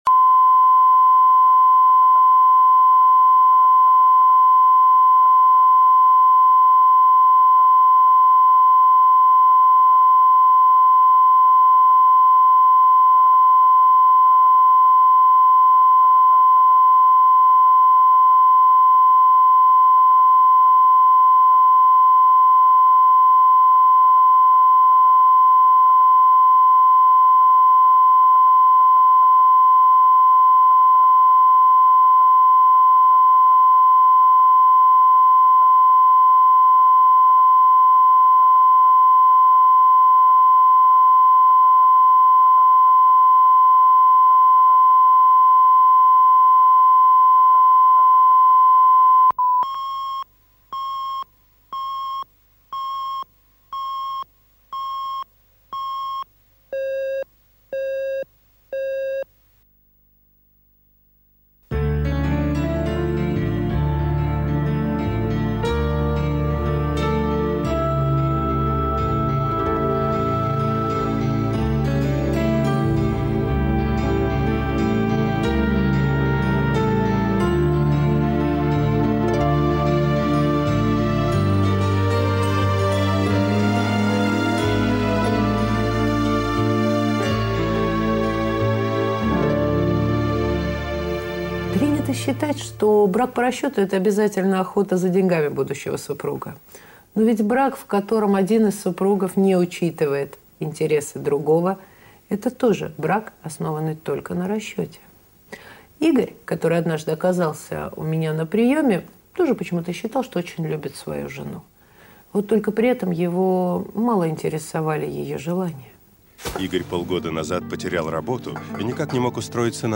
Аудиокнига Брак по расчету | Библиотека аудиокниг